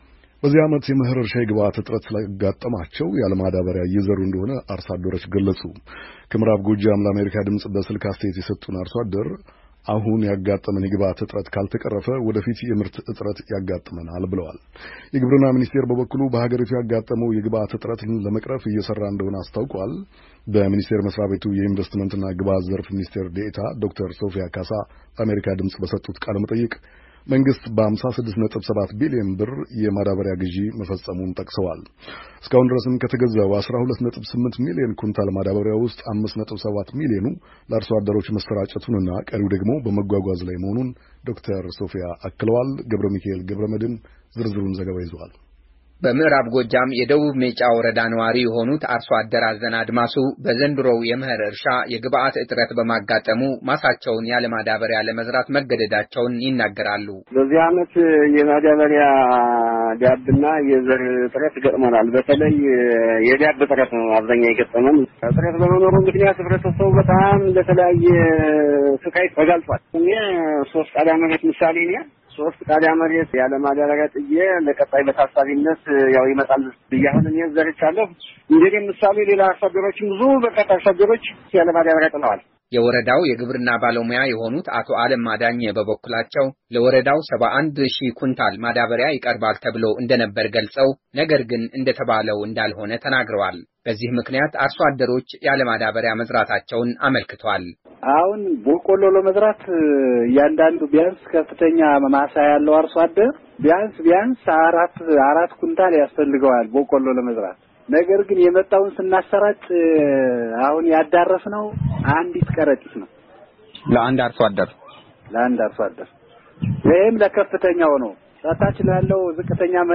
ከዐማራ ክልል ምዕራብ ጎጃም ዞን ሜጫ ወረዳ፣ ለአሜሪካ ድምፅ በስልክ አስተያየት የሰጡ አርሶ አደር፣ የግብአት እጥረት በማጋጠሙ፣ ማሳቸውን ያለማዳበሪያ ለመዝራት መገደዳቸውን ይናገራሉ፡፡ እጥረቱ ካልተቀረፈም፣ ወደፊት የምርት እጥረት እንዳይገጥማቸው ስጋቸውን አመልክተዋል፡፡